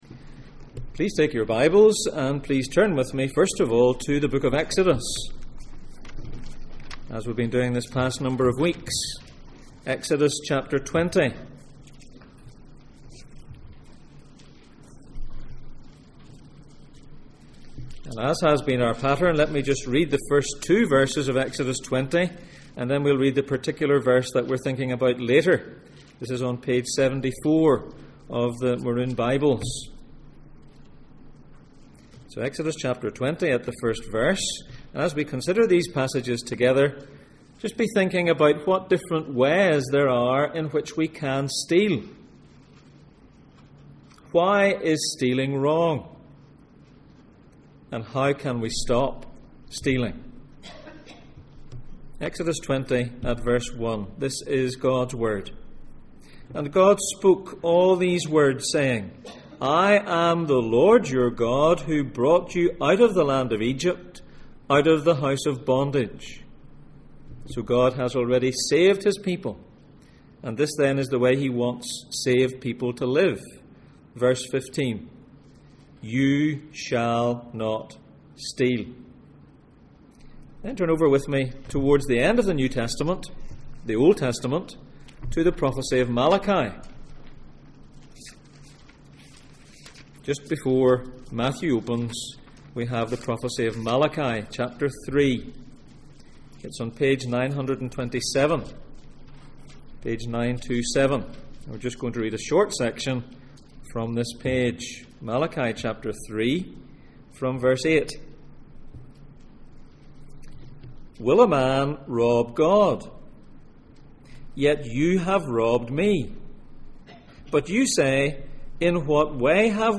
Gods instructions for life Passage: Exodus 20:1-2, Exodus 20:15, Malachi 3:8-12, Ephesians 4:25-5:2, Exodus 22:1, Deuteronomy 22:1-4, Deuteronomy 24:14-15 Service Type: Sunday Morning